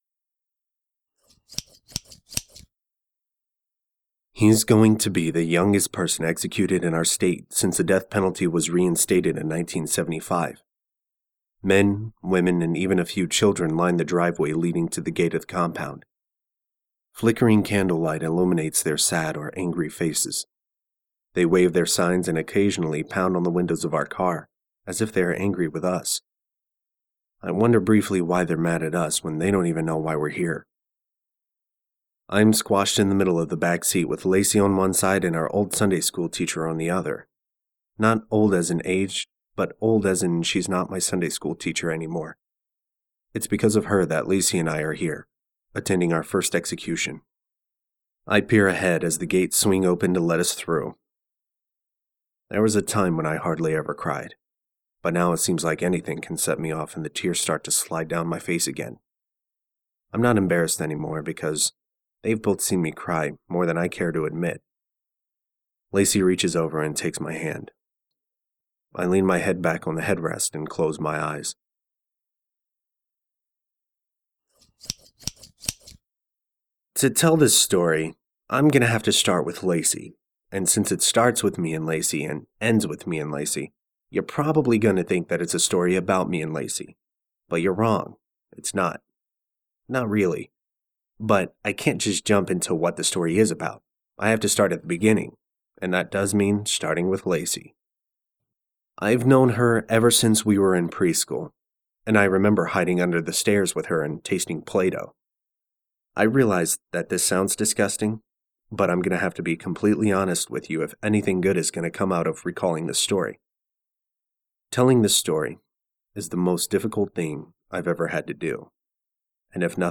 I'm also working on getting the audioversion of Chop, Chop finished and uploaded to Audible.